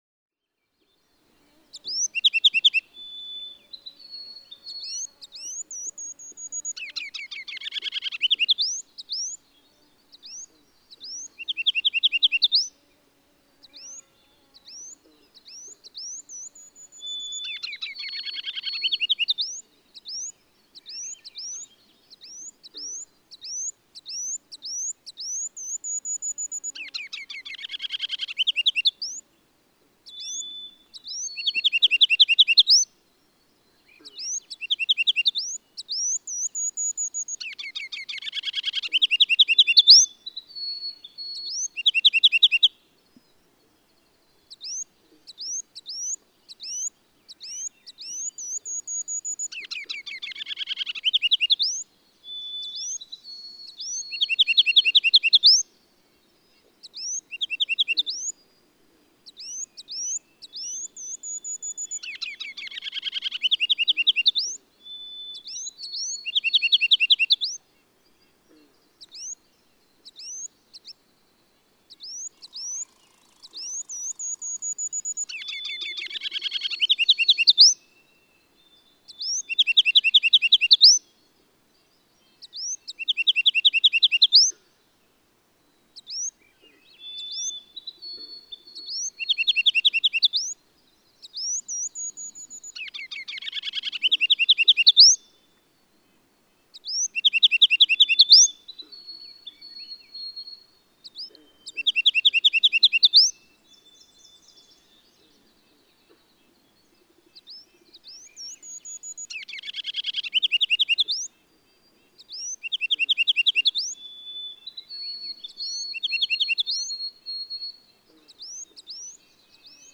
Ruby-crowned kinglet
Partial songs and call notes convey a different mood, but what exactly is this mood?
Westmanland, Maine.
387_Ruby-crowned_Kinglet.mp3